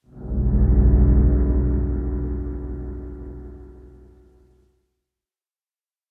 Minecraft Version Minecraft Version latest Latest Release | Latest Snapshot latest / assets / minecraft / sounds / ambient / cave / cave7.ogg Compare With Compare With Latest Release | Latest Snapshot
cave7.ogg